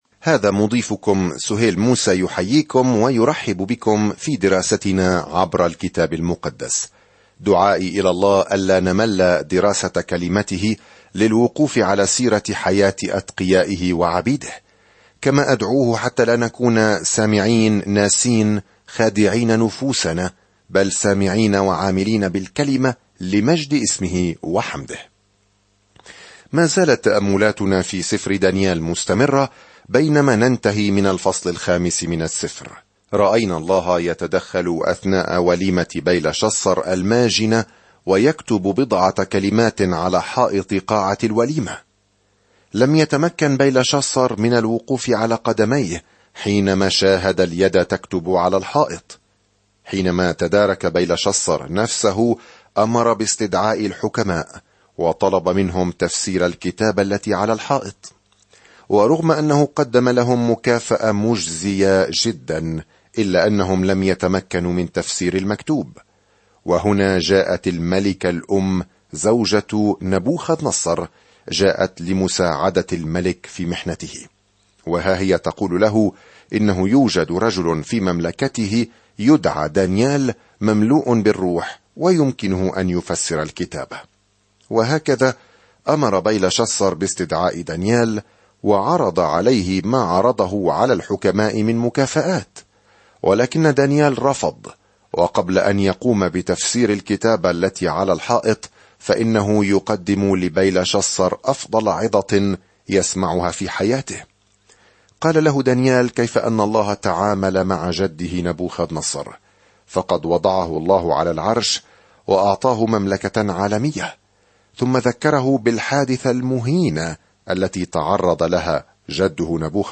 الكلمة دَانِيآل 25:5-31 دَانِيآل 1:6-3 يوم 12 ابدأ هذه الخطة يوم 14 عن هذه الخطة إن سفر دانيال هو عبارة عن سيرة ذاتية لرجل آمن بالله ورؤية نبوية لمن سيحكم العالم في النهاية. سافر يوميًا عبر دانيال وأنت تستمع إلى الدراسة الصوتية وتقرأ آيات مختارة من كلمة الله.